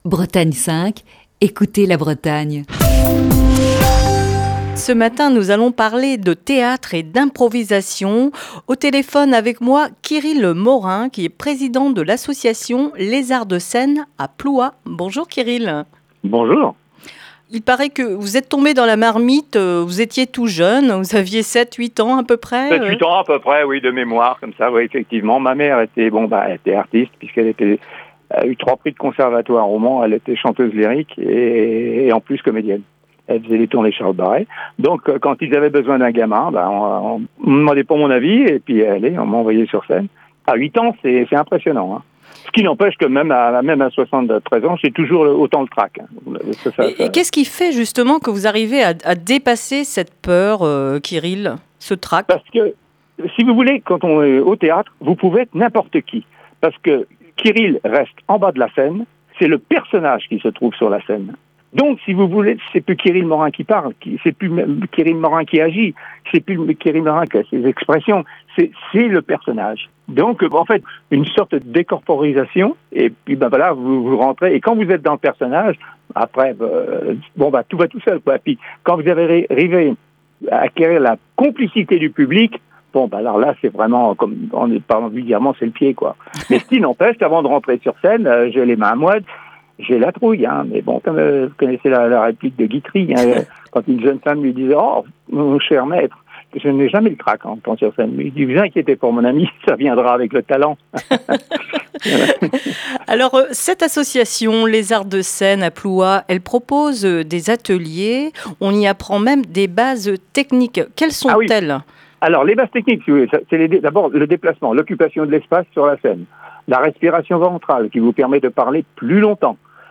Ce mardi dans le coup de fil du matin